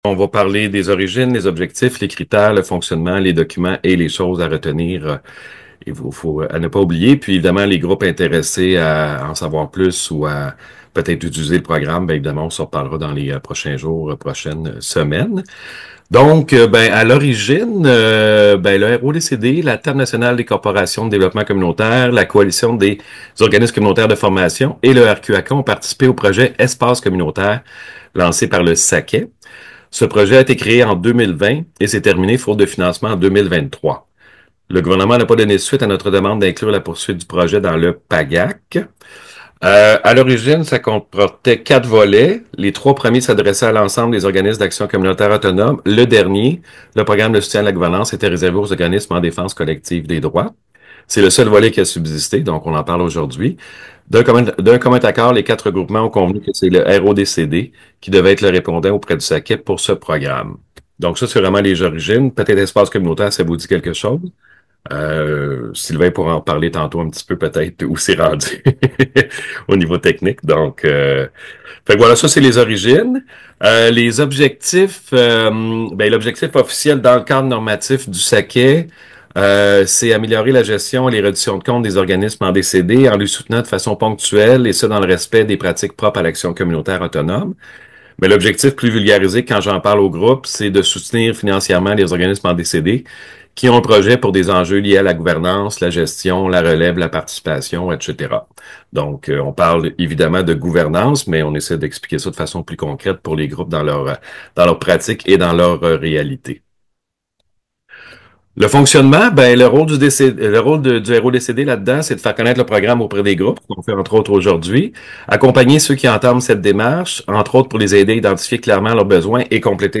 Merci aux personnes présentes lors de la présentation du projet Soutien à la gouvernance du 9 avril dernier!